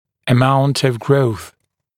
[ə’maunt əv grəuθ][э’маунт ов гроус]величина роста